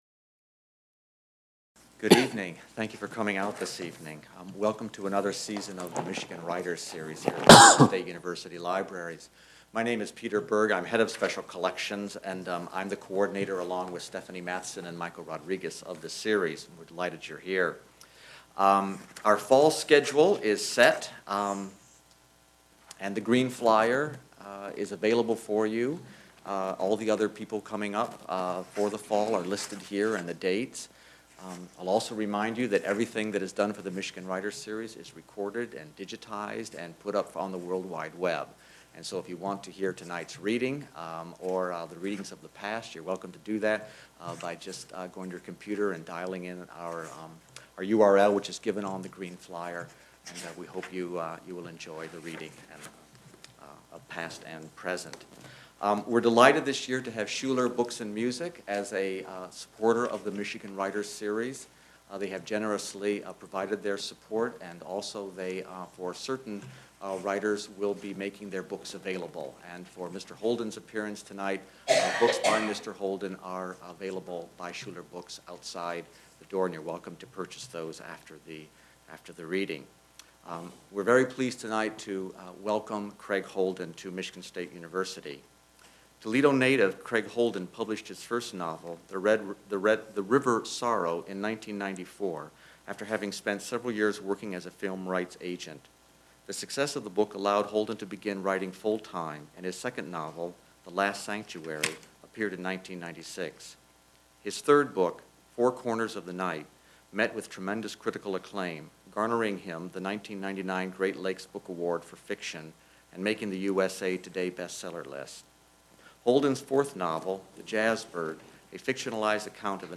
Part of the MSU Libraries' Michigan Writers Series. Held in the Main Library.